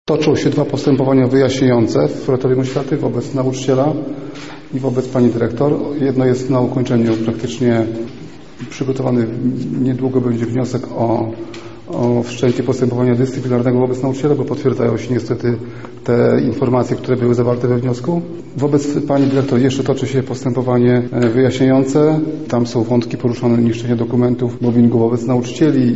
Tomasz Szabłowski– informuje lubelski kurator oświaty  Tomasz Szabłowski.